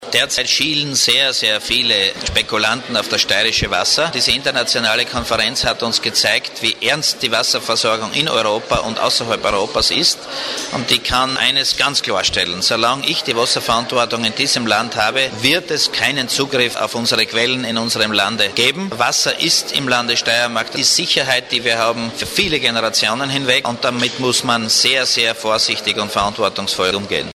Statements
Fragen an Landesrat Johann Seitinger.